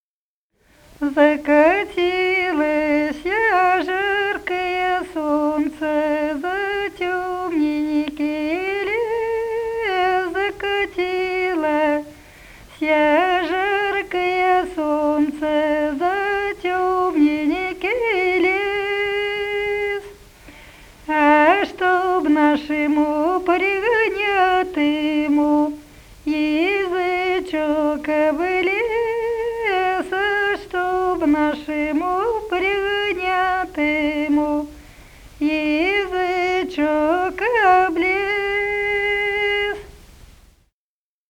Народные песни Смоленской области
«Закатилося жаркое солнце» (жнивная, батрацкая).